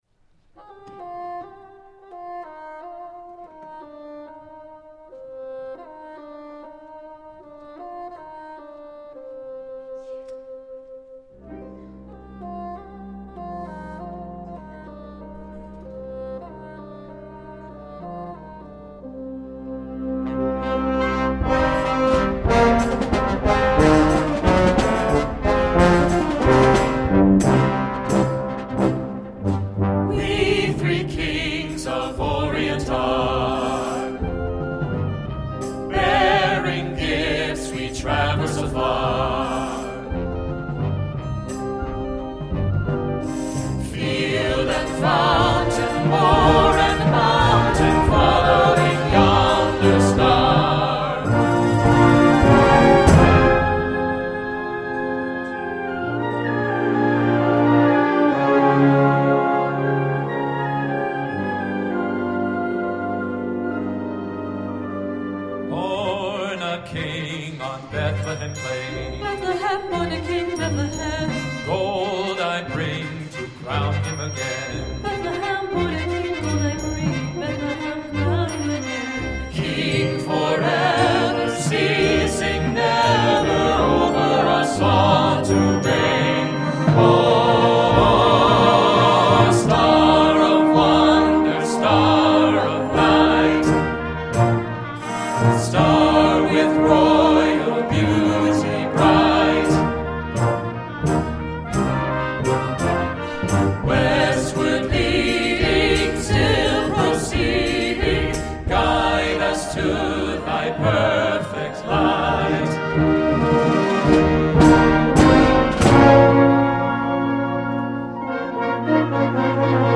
編成：吹奏楽
Timpani, Suspended Cymbal, Finger Cymbals
[Percussion 4] Marimba, Triangle, Crash Cymbals, Tam-tam